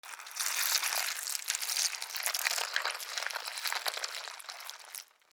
シリアルに1L紙パックから液体を注ぐ 02